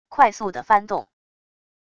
快速的翻动wav音频